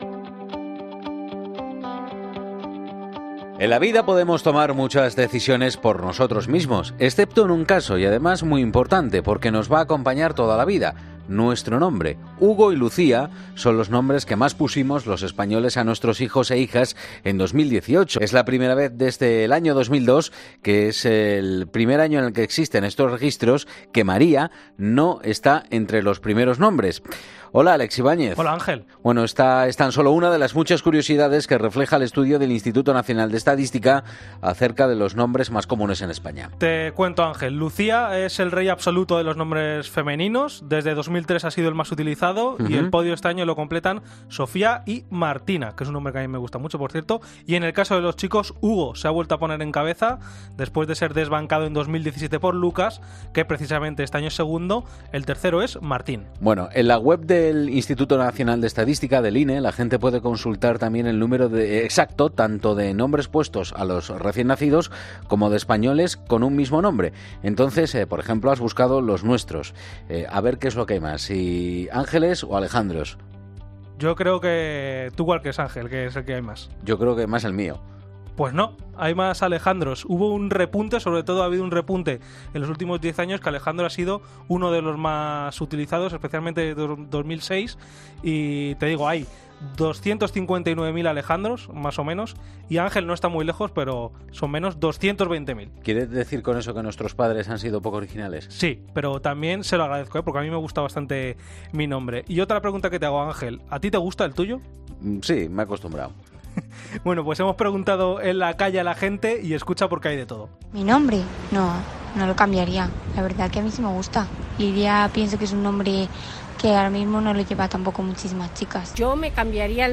Hemos salido a la calle a preguntar, por ejemplo, si a la gente le gusta su nombre.